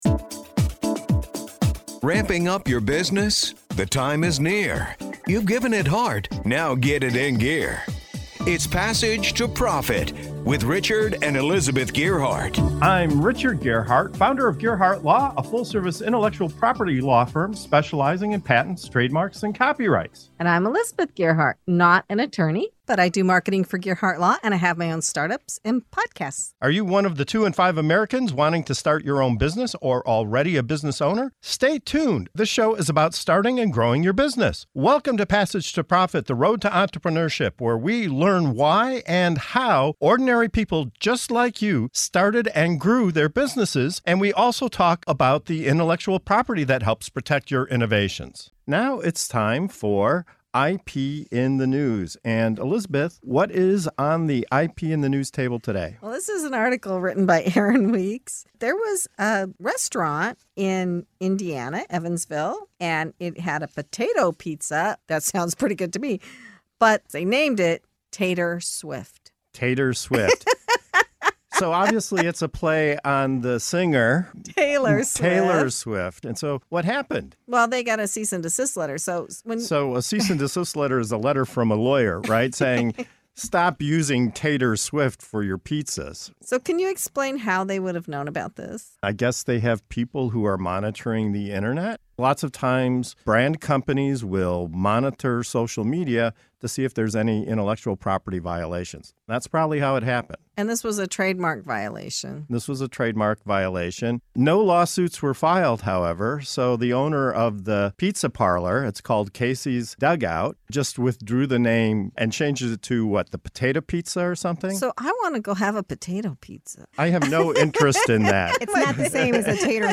With lively guest commentary, including a fiery debate on free speech versus trademark rights and the unexpected publicity boon for Casey’s Dugout, this segment serves a delicious slice of IP drama.